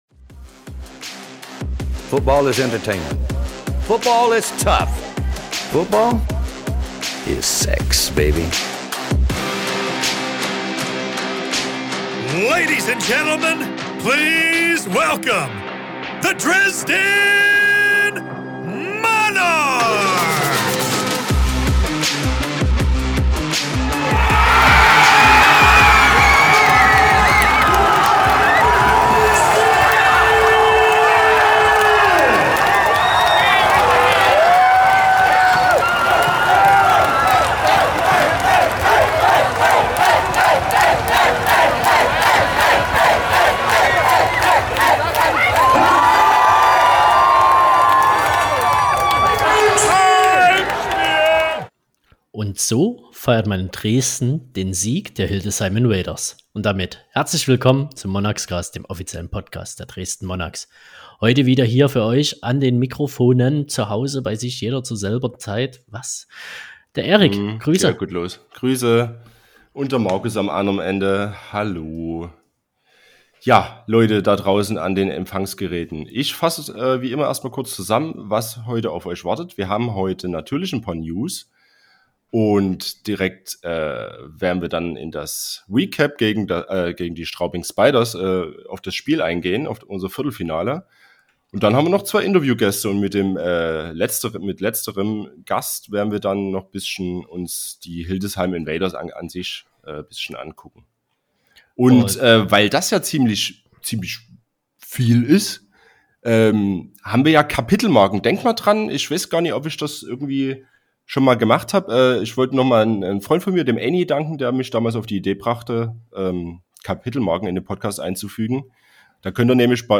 Allerdings haben wir uns nicht lumpen lassen, denn auch einen Monarchs Spieler konnten wir noch vor das Mikrofon bekommen.